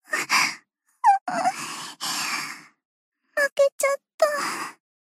贡献 ） 分类:蔚蓝档案语音 协议:Copyright 您不可以覆盖此文件。
BA_V_Koharu_Tactic_Defeat_1.ogg